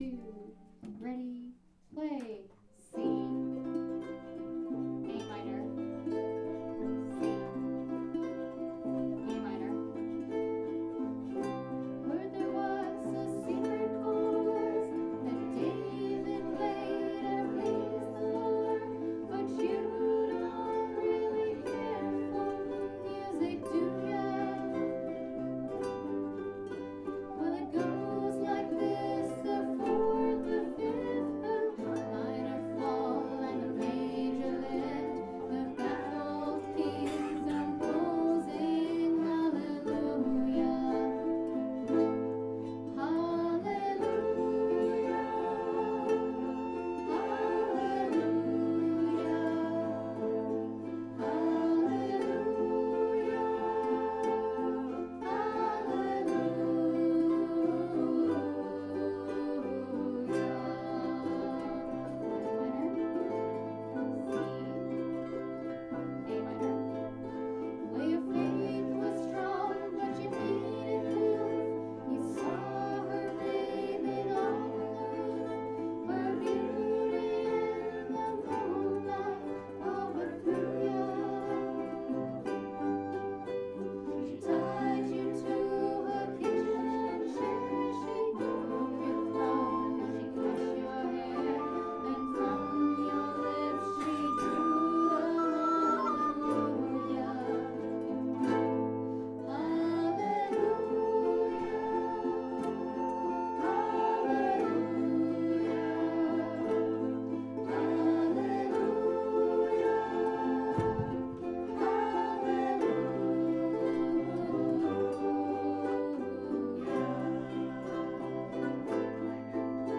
Peninsula Ukulele Group Woodside Jam